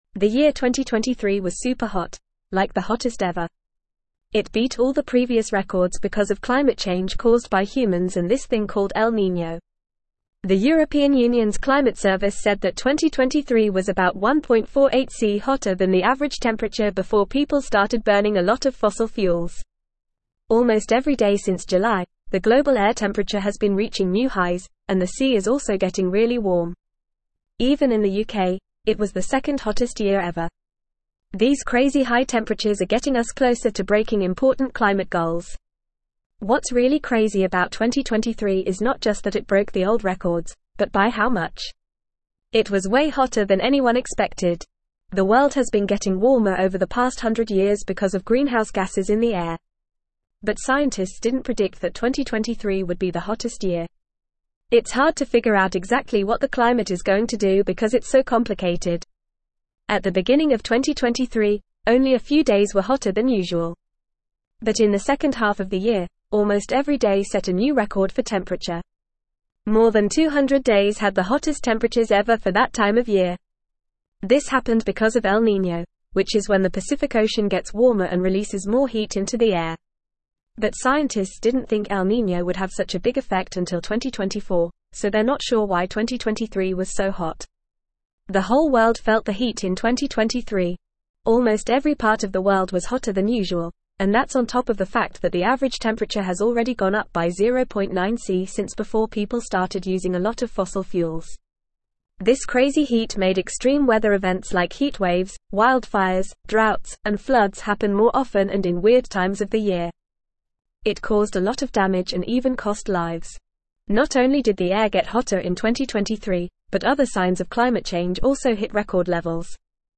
Fast
English-Newsroom-Upper-Intermediate-FAST-Reading-2023-Hottest-Year-on-Record-Urgent-Climate-Action-Needed.mp3